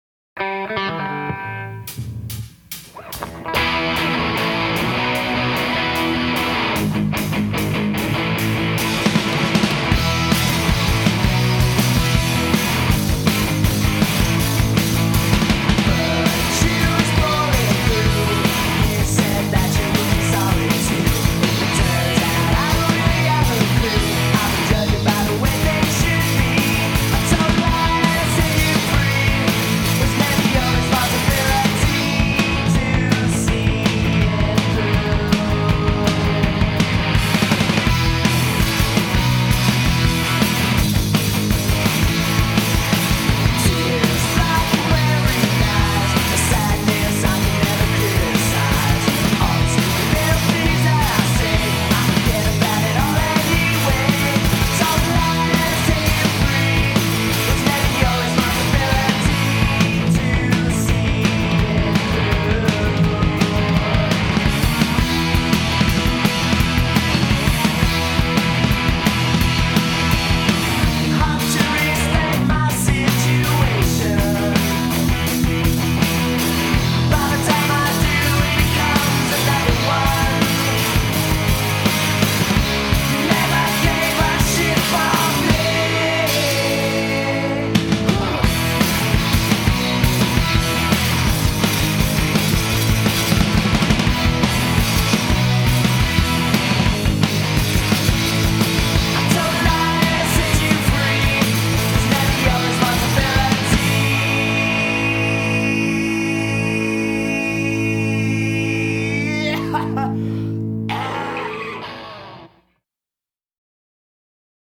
Two-minute pop gems.